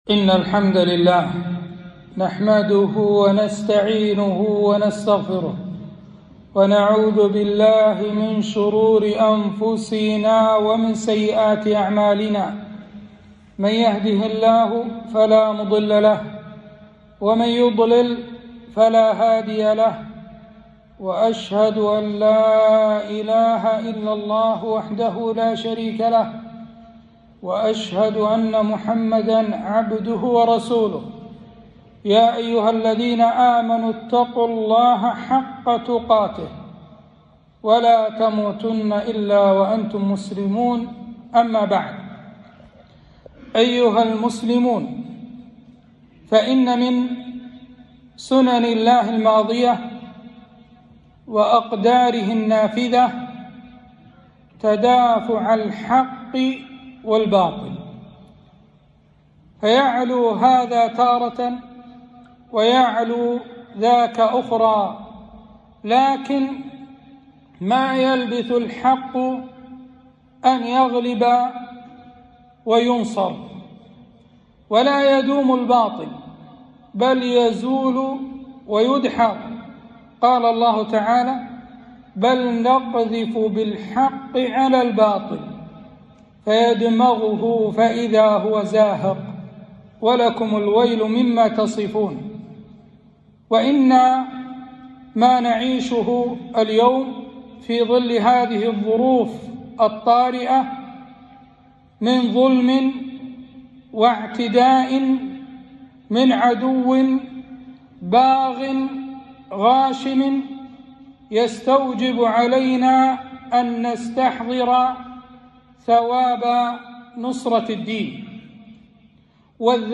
خطبة - الجهاد ذروة سنام الإسلام